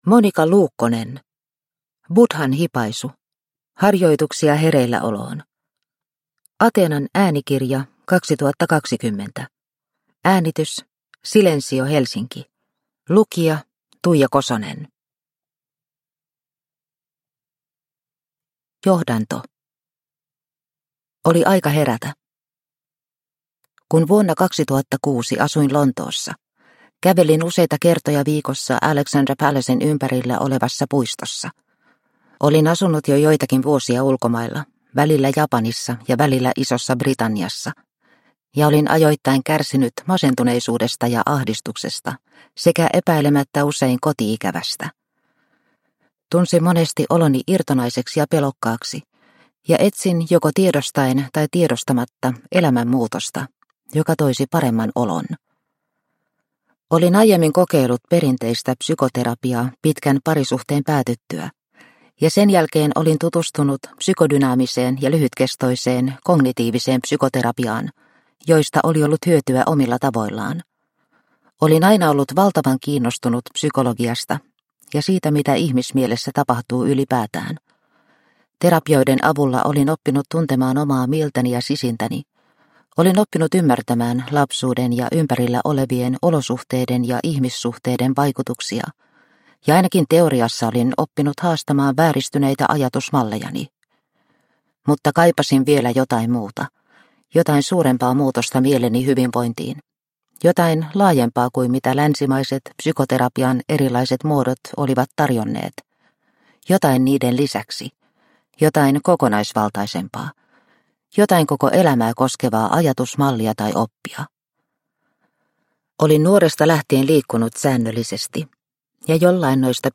Buddhan hipaisu – Ljudbok